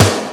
Snares
Montreal Snare.wav